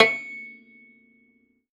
53x-pno12-C5.wav